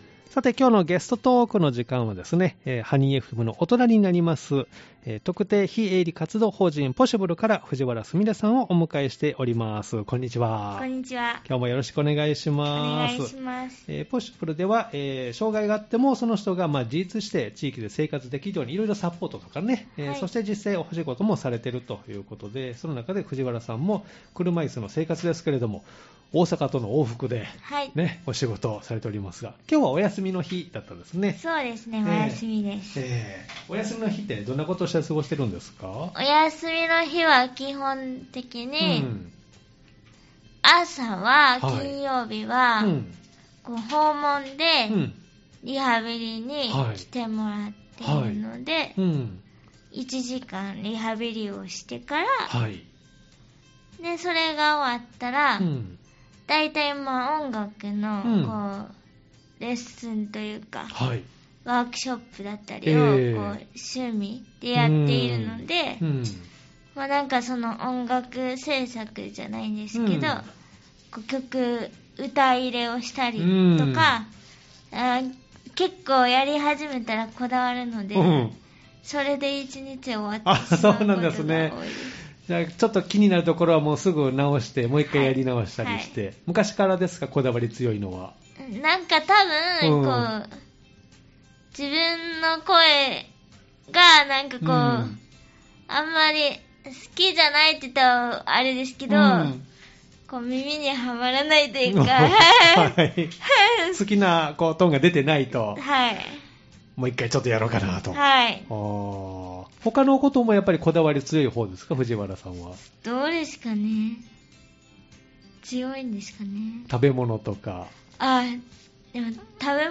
アーカイブ放送でも聴くことができます！